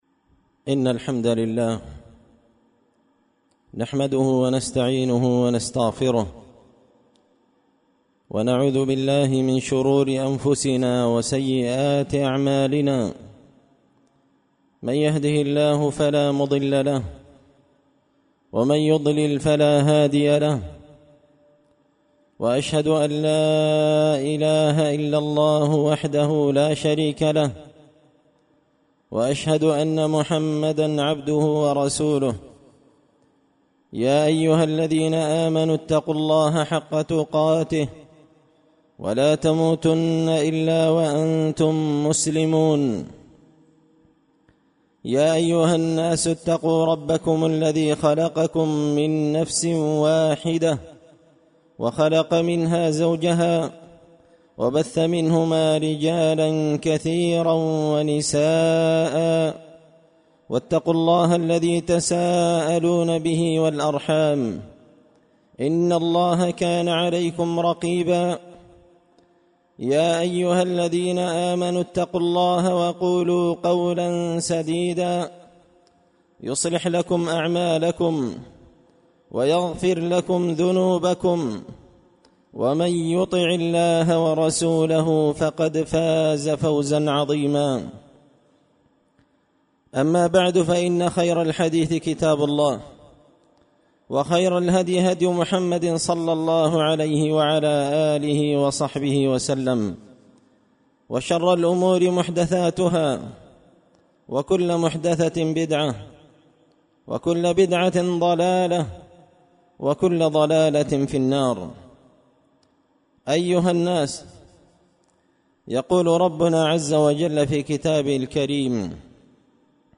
خطبة جمعة بعنوان – نعمة الأمن
دار الحديث بمسجد الفرقان ـ قشن ـ المهرة ـ اليمن